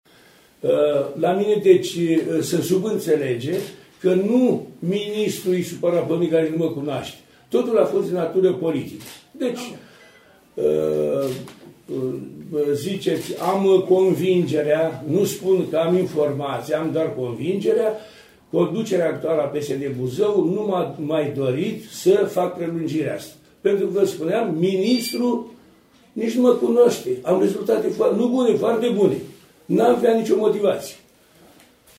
a declarat într-o conferință de presă că debarcarea sa de la conducerea unității spitalicești are doar motive politice și nicidecum rezultatele activității sale de până acum care